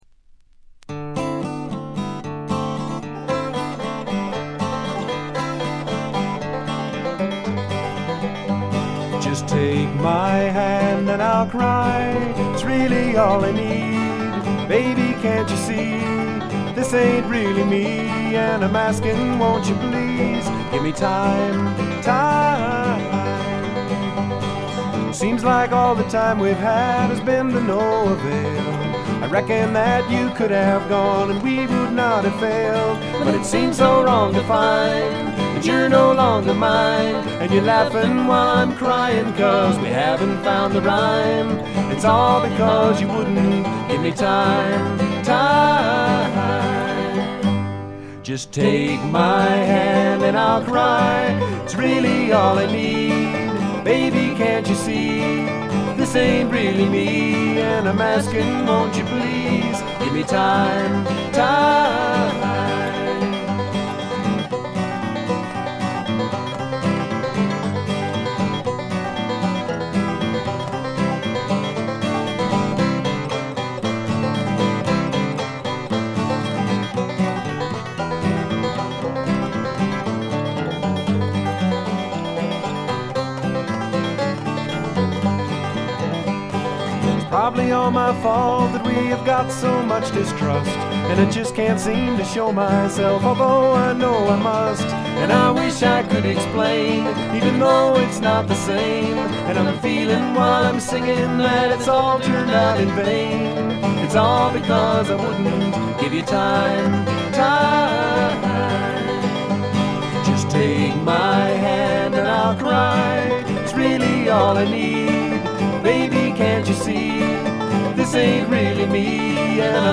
ほとんどノイズ感無し。
全体に静謐で、ジャケットのようにほの暗いモノクロームな世界。
試聴曲は現品からの取り込み音源です。
Vocals, Guitars, Harmonica